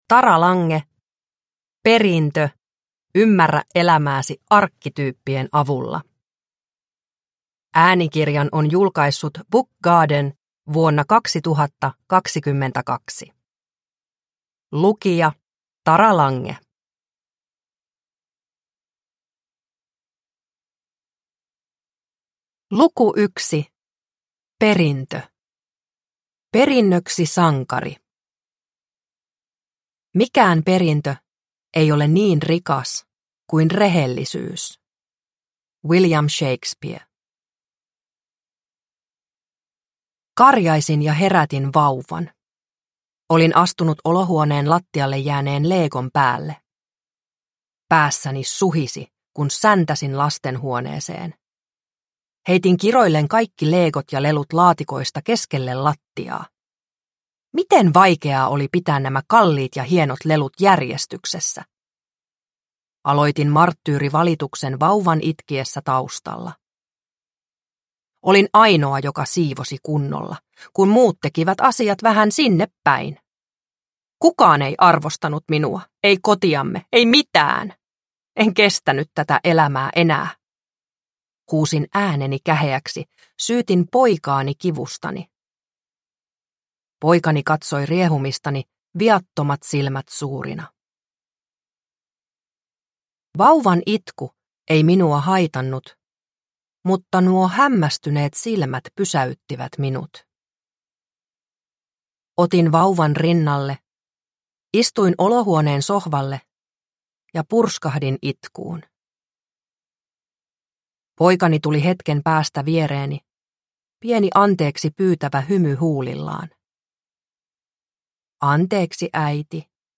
Perintö – Ljudbok – Laddas ner